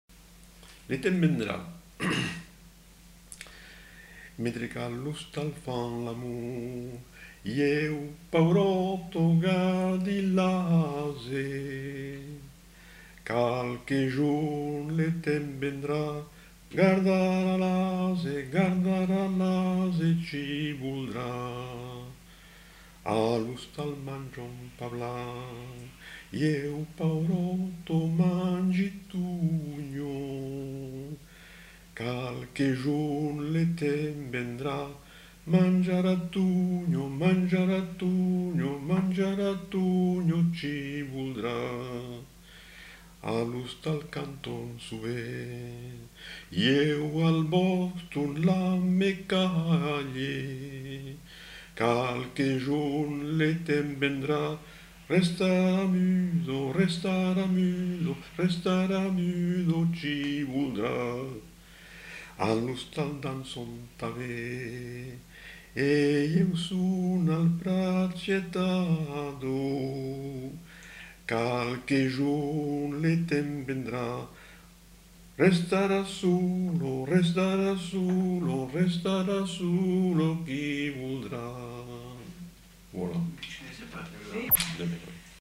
Aire culturelle : Quercorb
Lieu : Rivel
Genre : chant
Effectif : 1
Type de voix : voix d'homme
Production du son : chanté